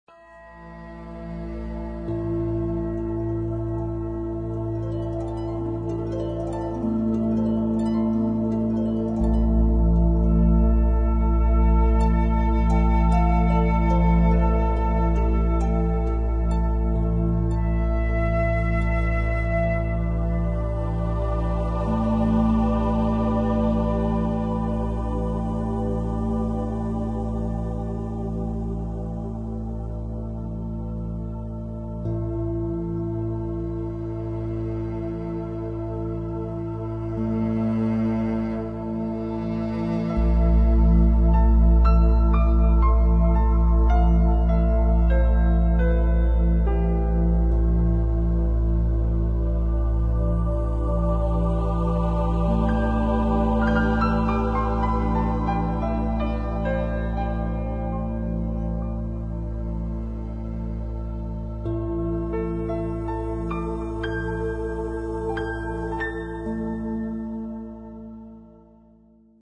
Naturgeräusche